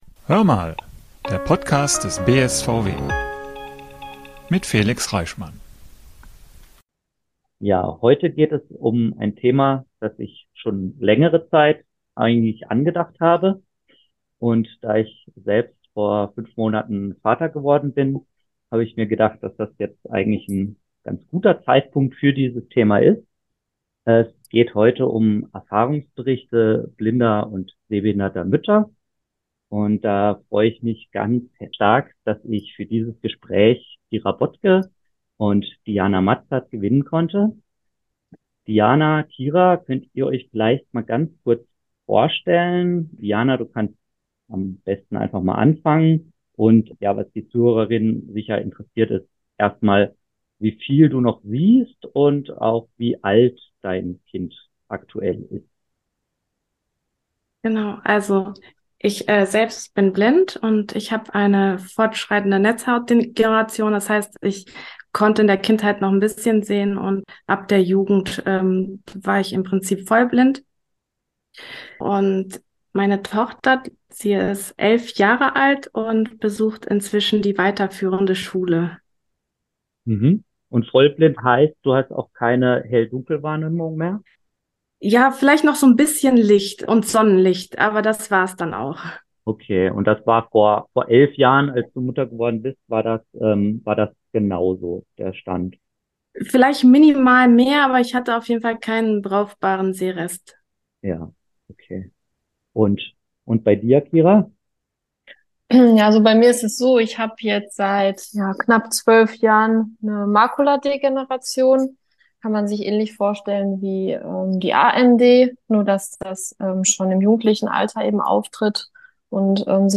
Beschreibung vor 3 Jahren In der heutigen Podcast-Episode sprechen wir mit einer blinden und einer sehbehinderten Mutter über ihre Erfahrungen und Herausforderungen im Alltag. In einem offenen und ehrlichen Gespräch teilen sie Ängste und Sorgen, geben aber auch wertvolle Ratschläge für werdende Eltern, bei denen einer oder beide Partner blind oder sehbehindert sind.